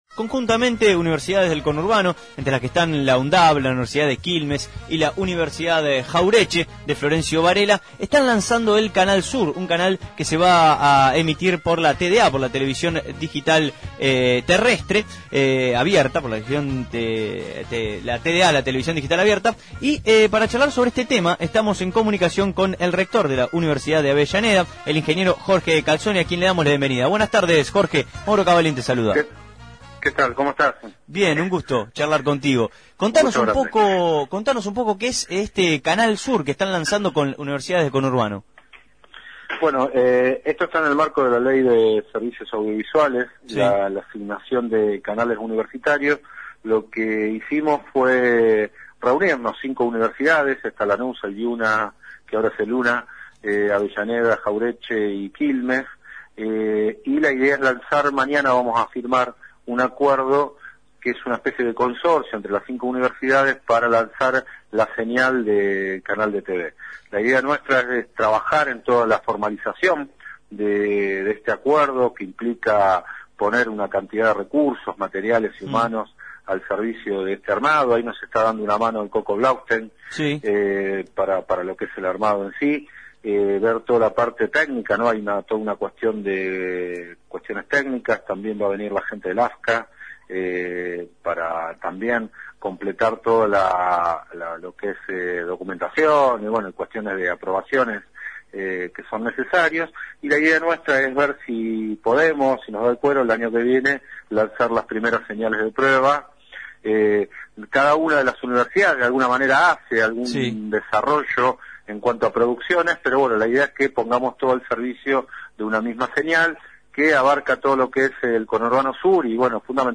calzoniEl rector de la UNDAV y presidente del ACUMAR, Ingeniero Jorge Calzoni, habló en el programa Abramos La Boca, sobre la firma de convenios para la la creación de la emisora para la TDA Canal Sur, primer canal inter-universitario, donde ademas de la universidad de Avellaneda, también participaran la Universidad Nacional de Lanús (UNLa), la Universidad Nacional de Quilmes (UNQ), la Universidad Arturo Jauretche (UNAJ) de Florencio Varela y la Universidad Nacional de las Artes (UNA).